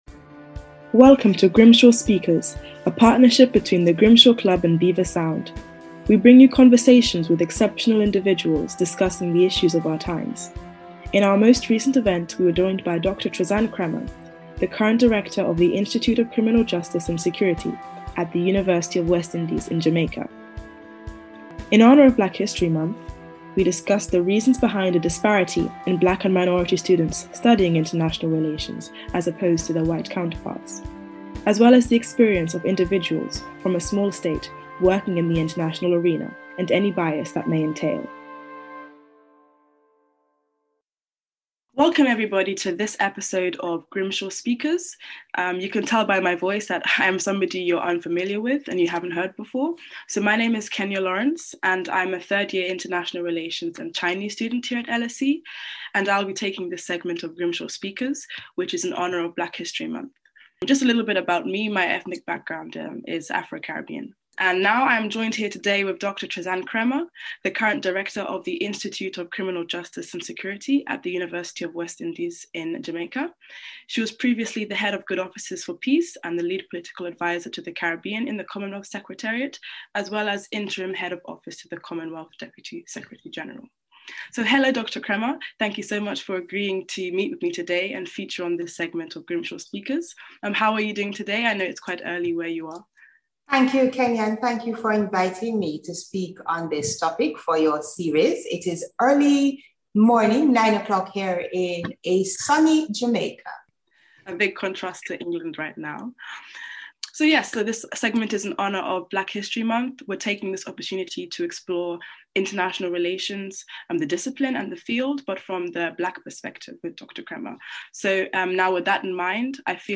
Our first episode of Grimshaw Speakers for this academic year is in honour of Black History Month. In this conversation